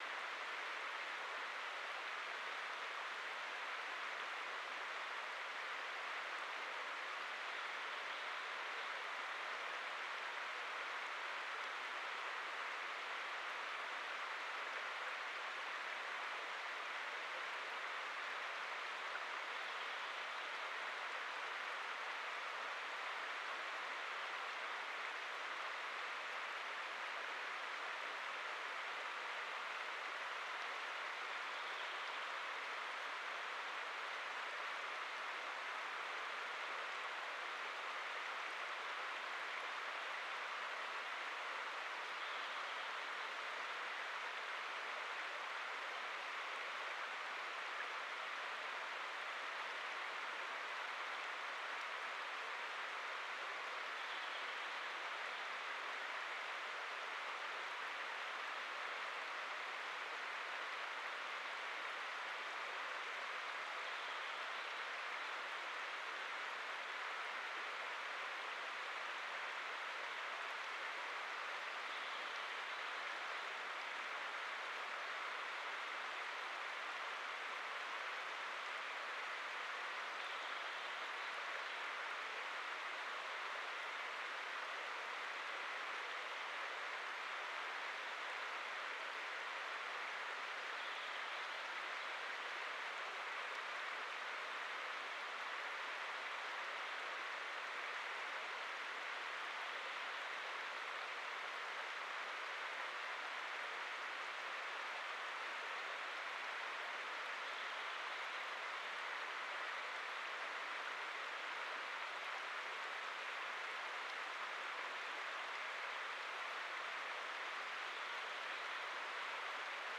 Quellrauschen2000.mp3